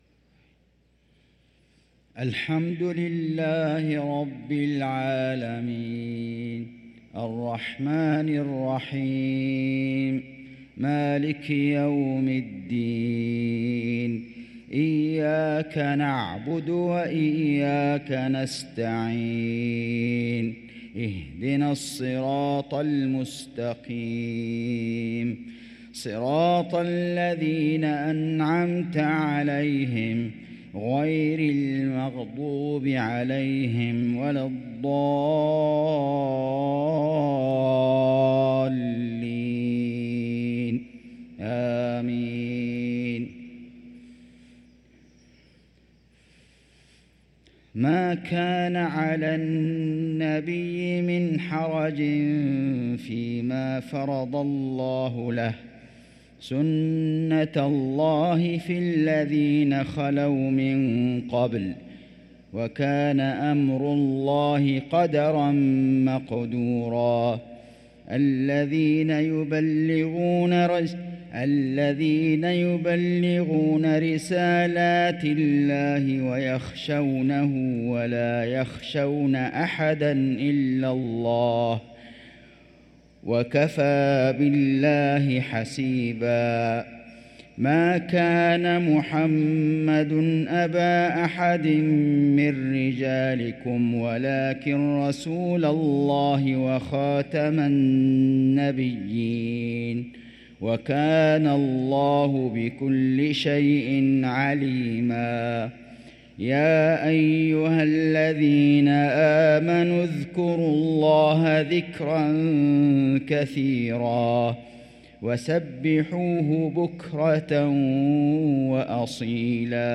صلاة المغرب للقارئ عبدالله البعيجان 9 جمادي الأول 1445 هـ
تِلَاوَات الْحَرَمَيْن .